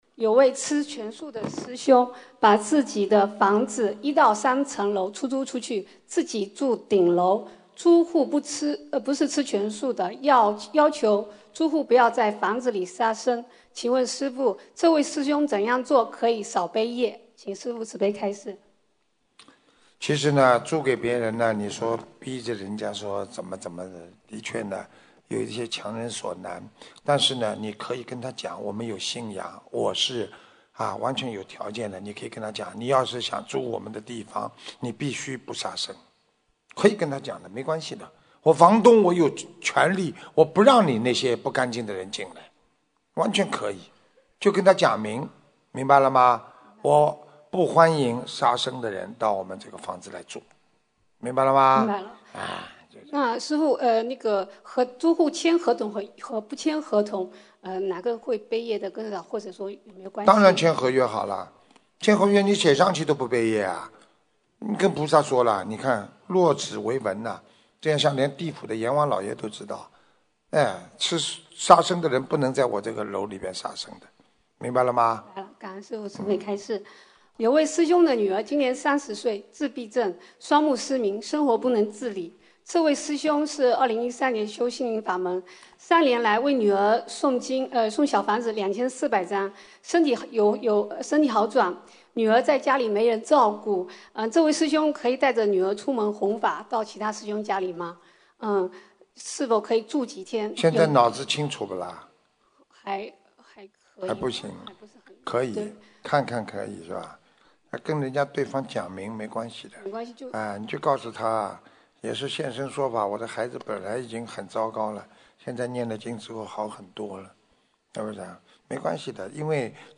吉隆坡世界佛友见面会共修组提问161224